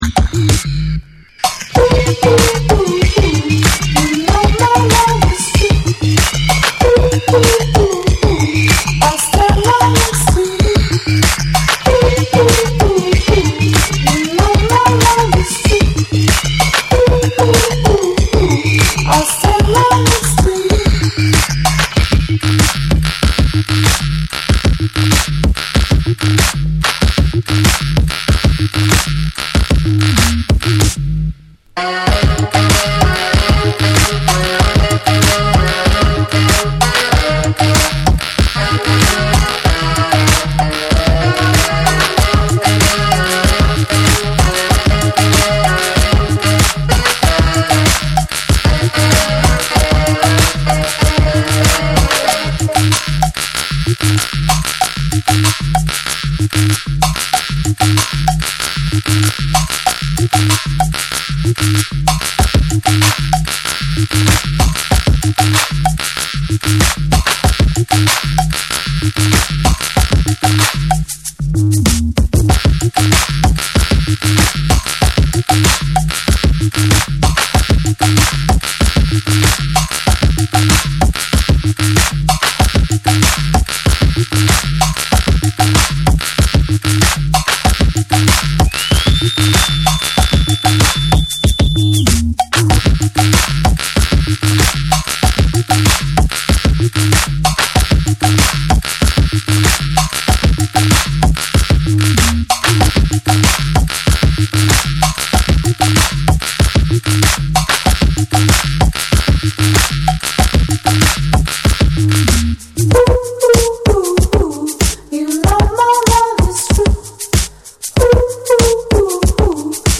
スローモーなビートとパーカッシブなリズムが絡み合うダンサブルなディスコ・トラック。
80年代のニューウェーブ的なムードも感じられる
TECHNO & HOUSE